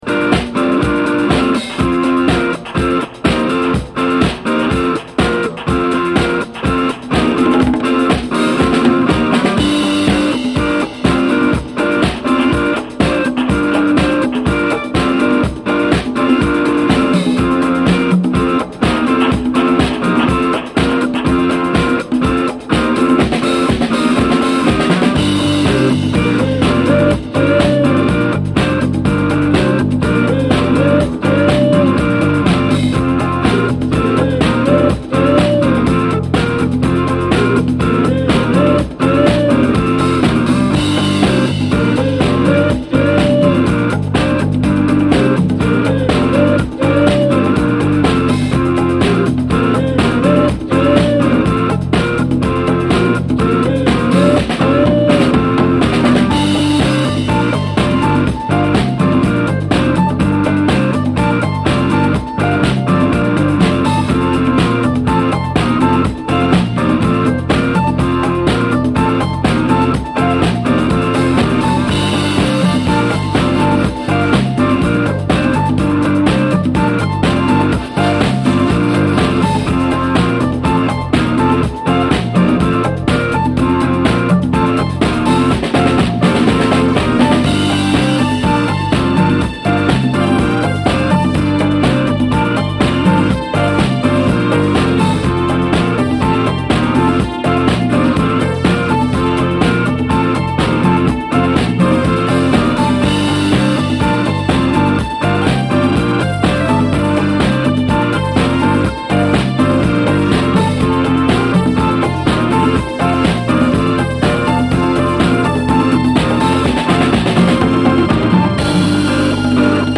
ジャズ・ファンク〜 ディスコ等あらゆる ブラックミュージックを昇華した新世代ダンスミュージック！